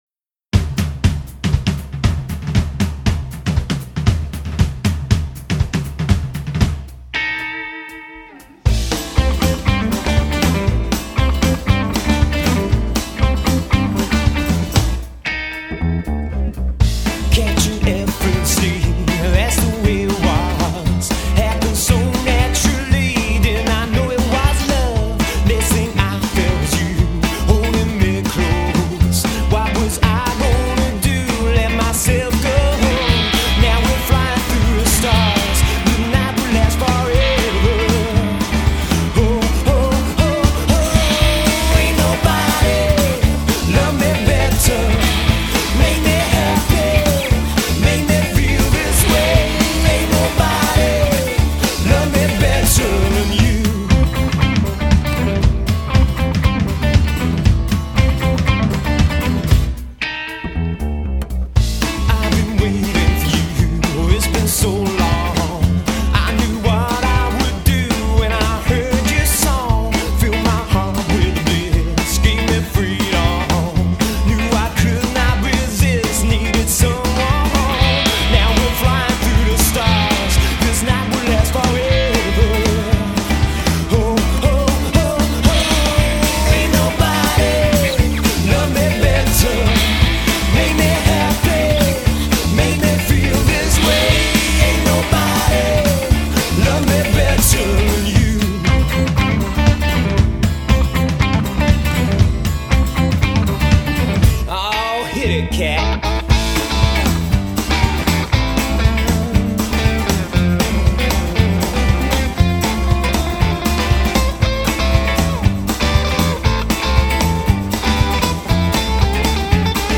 pop'n'roll á la boss hoss
That’s what we call: POP’N’ROLL !